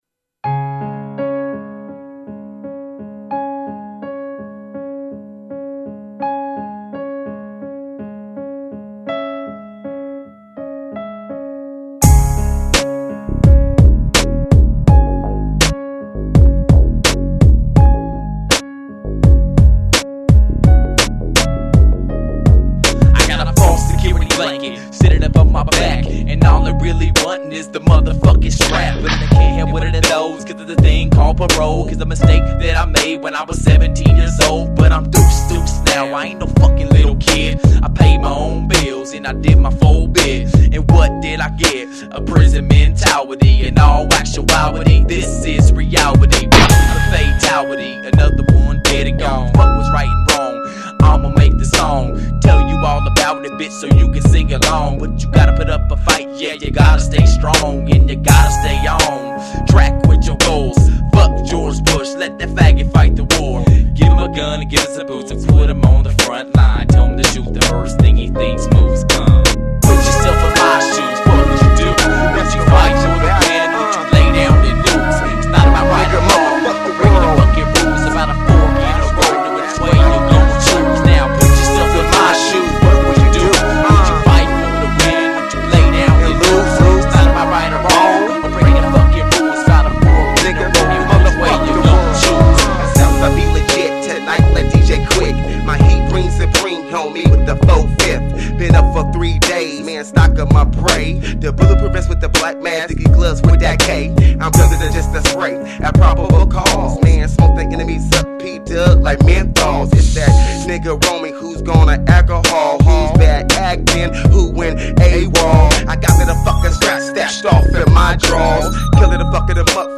Gangster Rap